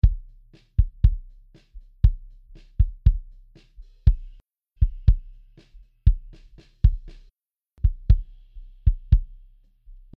Kick Out Mic: